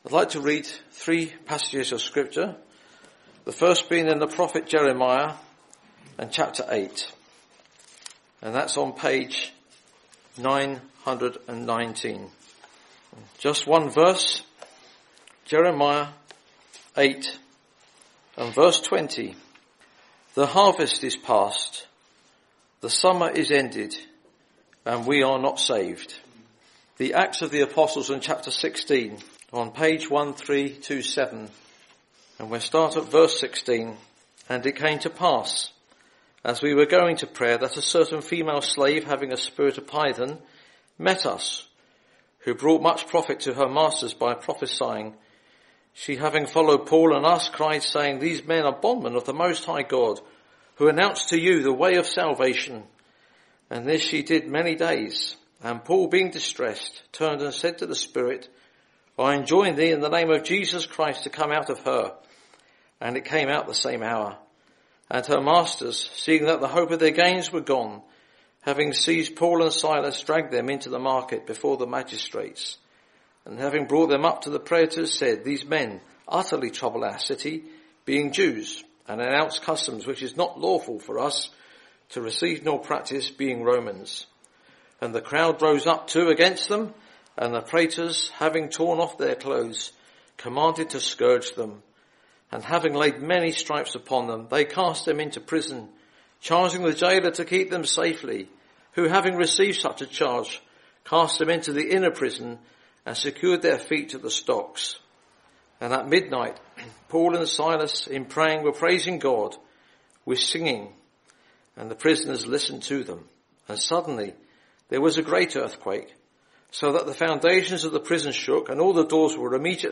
The Gospel is preached to announce the way of salvation that God has provided in His Son, the Lord Jesus Christ. Listen to this Gospel preaching and discover how you can be SAVED from your sins because of the work of Christ.